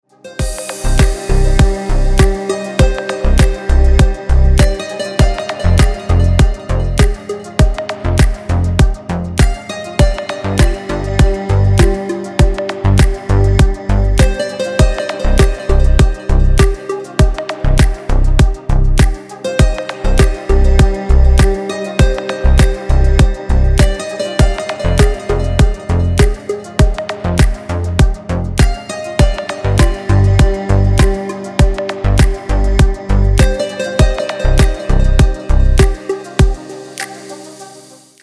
Красивые мелодии и рингтоны